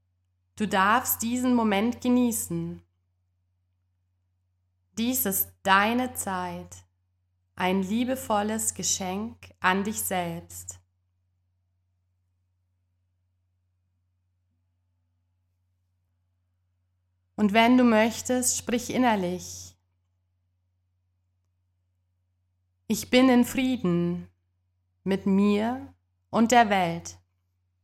• Format: Geführte Meditationen (Audio-Dateien)